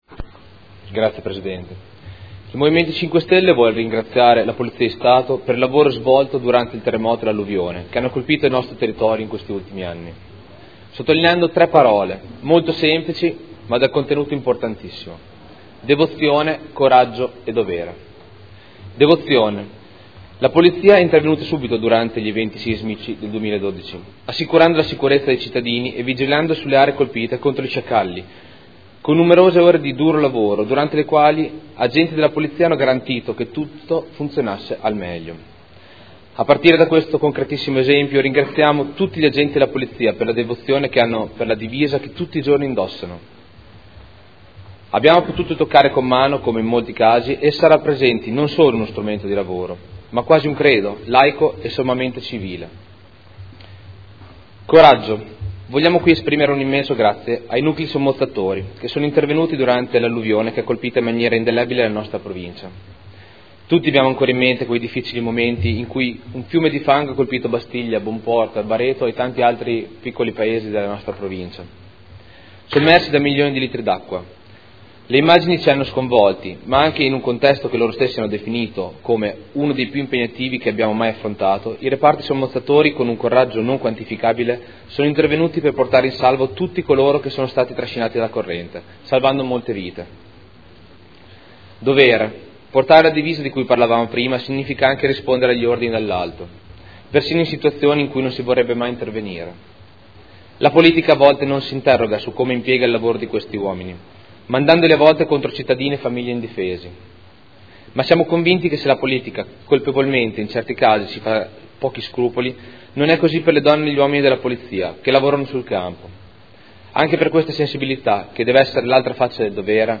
Seduta del 21/09/2015.
Dichiarazioni di voto